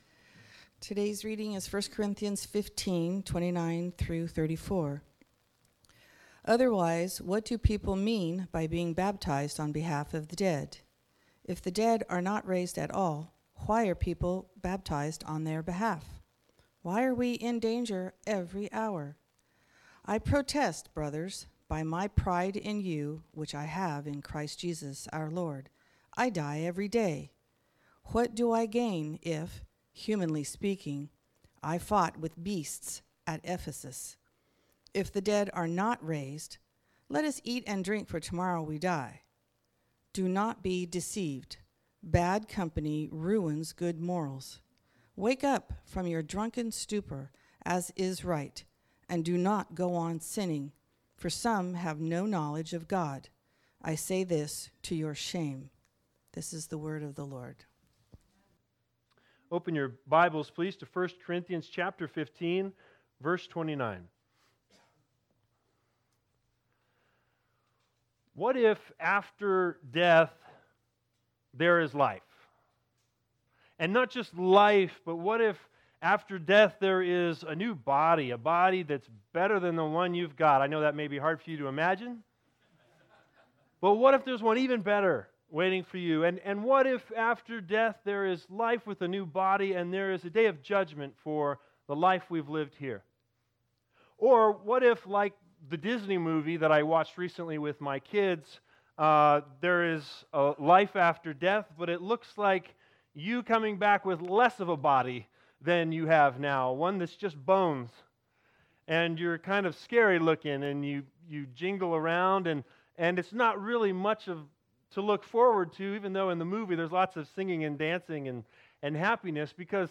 01/19/2020 Doctrine Matters Preacher